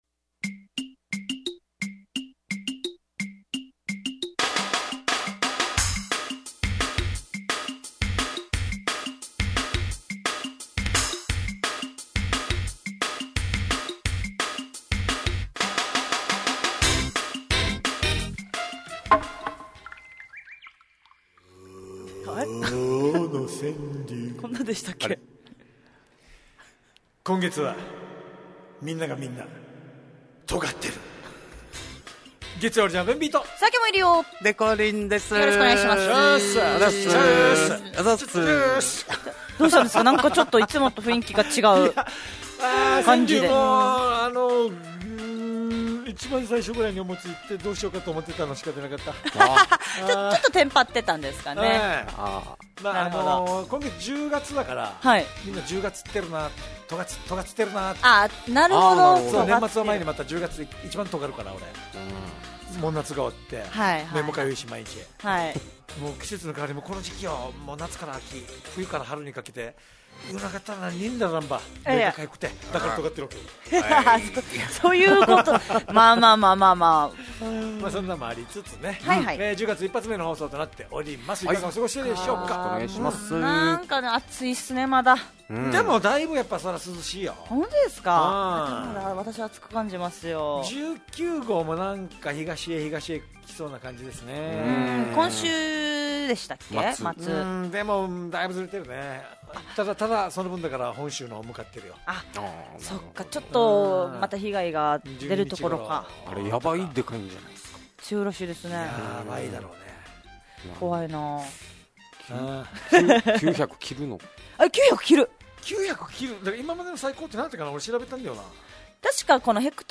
fm那覇がお届けする沖縄のお笑い集団・オリジンメンバー出演のバラエティ番組のオリジンアワー